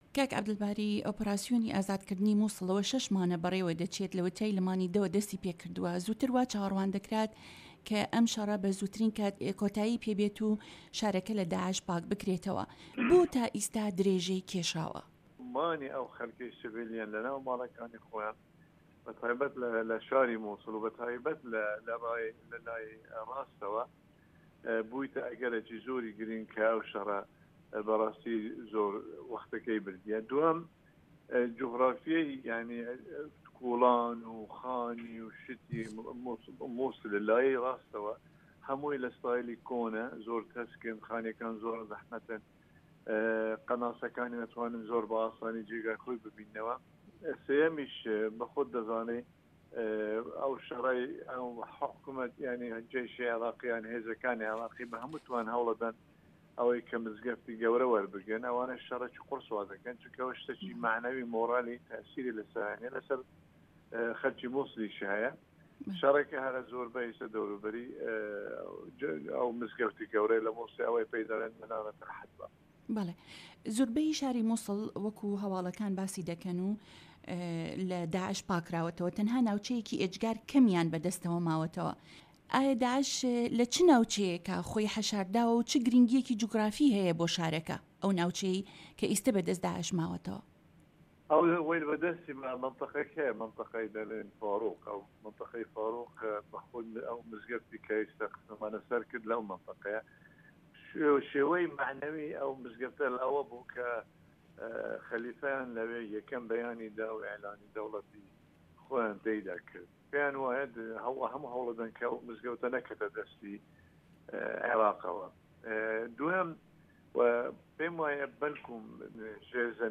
گفتوگۆ لەگەڵ عەبدوئەلباری زێباری